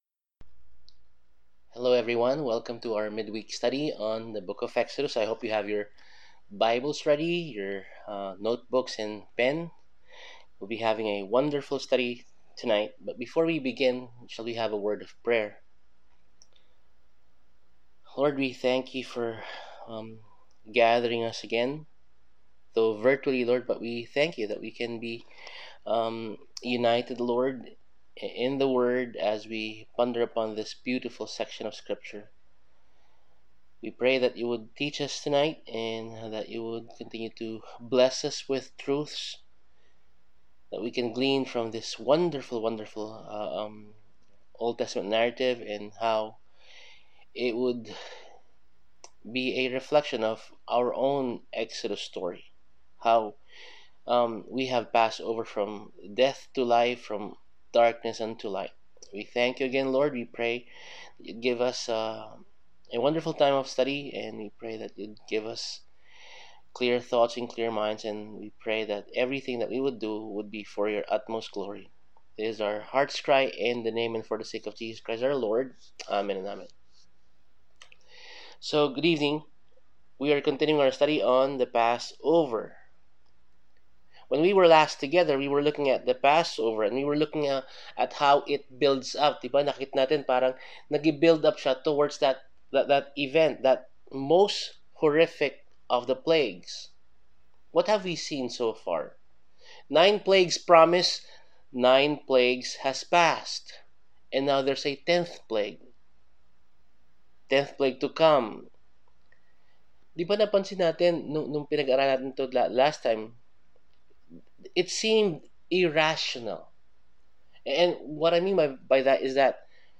Sermon Outline